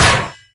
CosmicRageSounds / ogg / general / combat / armor / 5.ogg